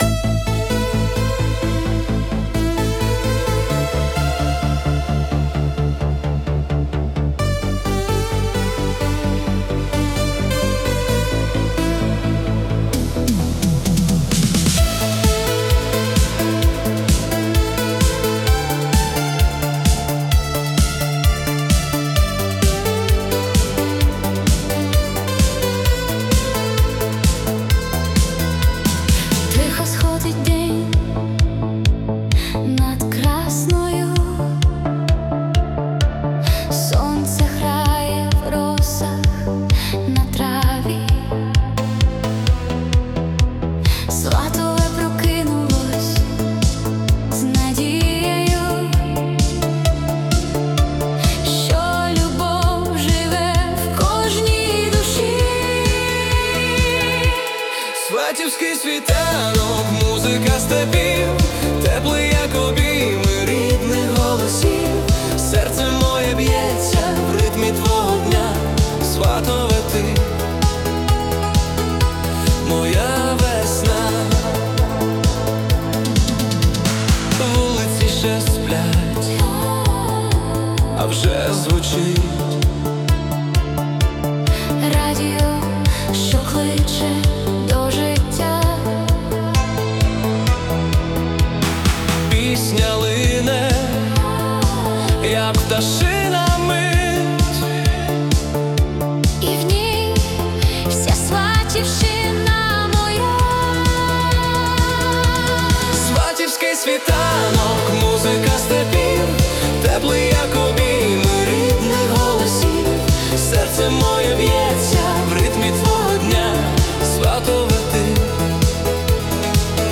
🎵 Жанр: Italo Disco / Retro Synthpop
Фінал пісні звучить особливо проникливо.